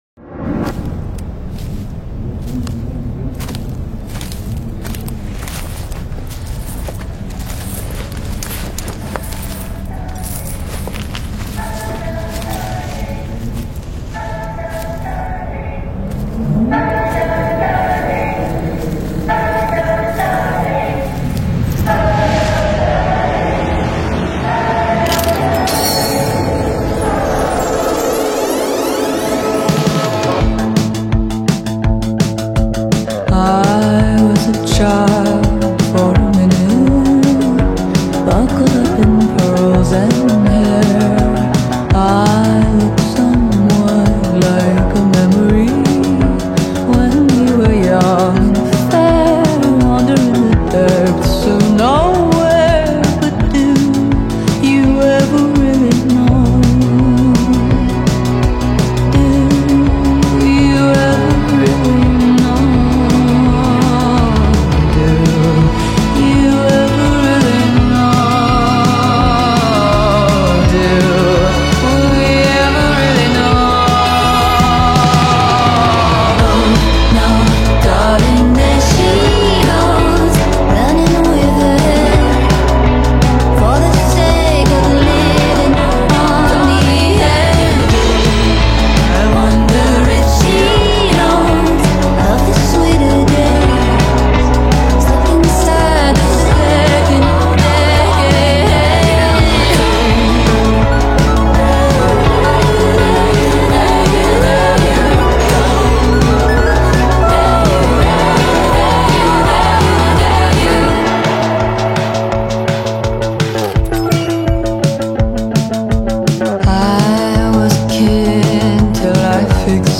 Hip Hop
Singer-songwriter
mesmerizing harmony